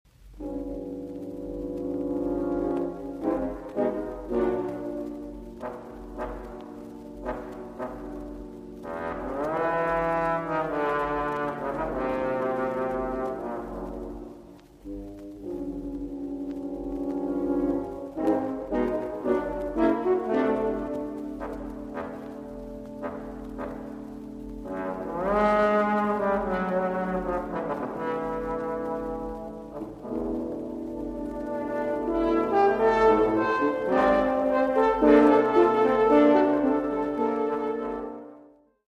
для трех валторн и тромбона